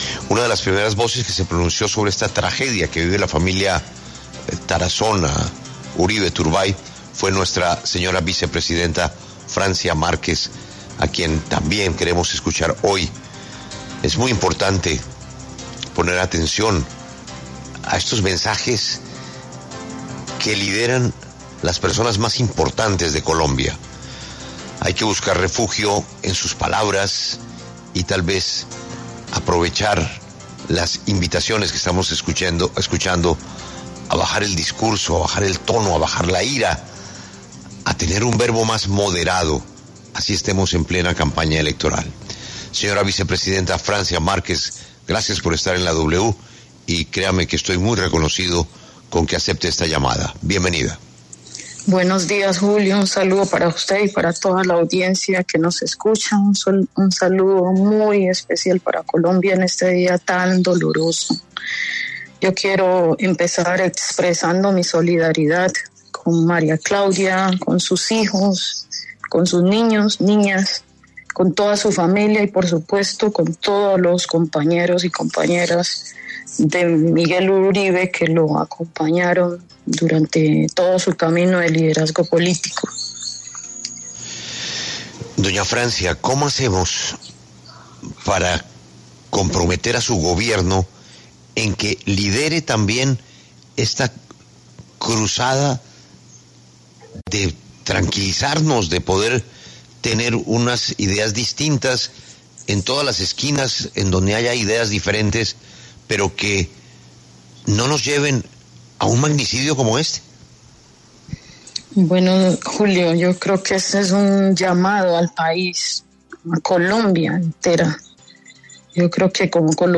La vicepresidenta Francia Márquez conversó con La W sobre el magnicidio del precandidato presidencial Miguel Uribe y expresó sus opiniones sobre el tema.